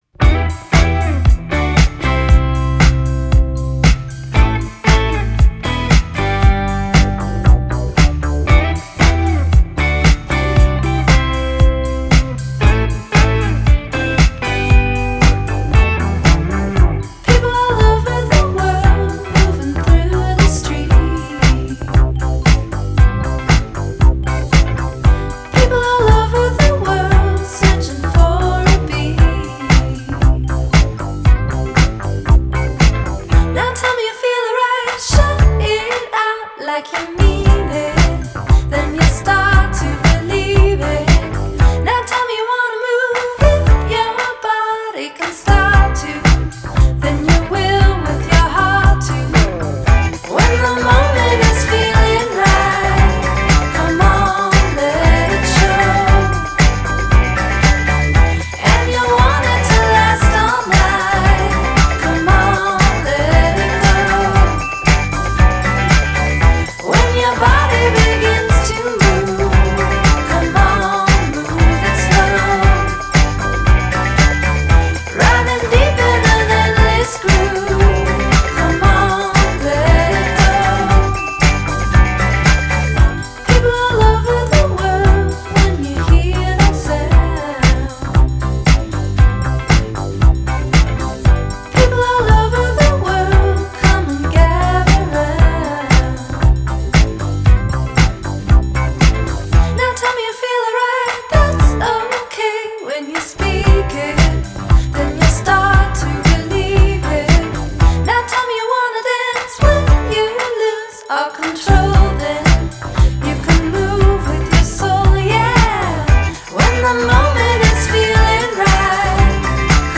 Genre: Indie Pop, Synthpop, Female Vocal